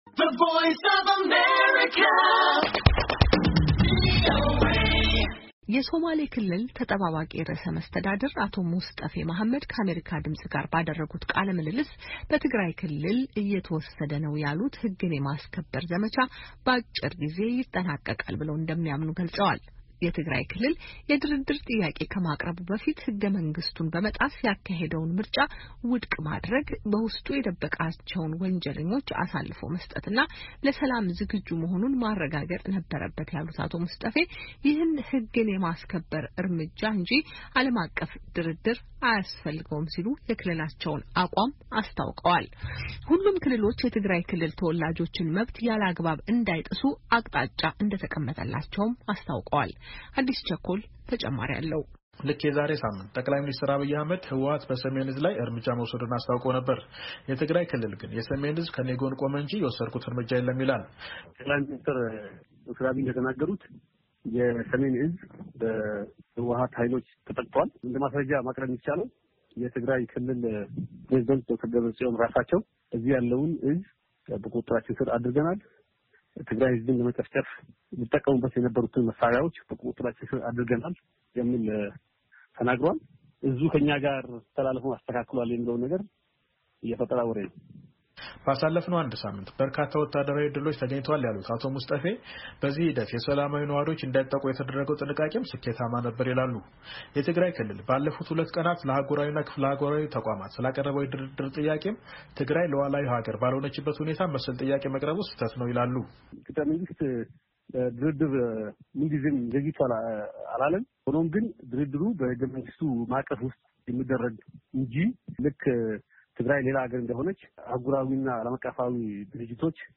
የሶማሌ ክልል አስተማማኝ ሰላም እንዳሰፈነና የፌዴራል መንግሥትንም በሚጠበቅበት ሁሉ ለመደገፍ ዝግጁ እንደሆነ አስታወቀ። የክልሉ ተጠባባቂ ርዕሰ መስተዳድር አቶ ሙስጠፌ መሀመድ ከቪኦኤ ጋር ባደረጉት ቃለ ምልልስ “በትግራይ ክልል እየተወሰደ ነው” ያሉት ህግን የማስከበር ዘመቻ በአጭር ጊዜ ይጠናቀቃል ብለው እንደሚያምኑ ገልጸዋል።